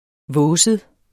Udtale [ ˈvɔːsəð ]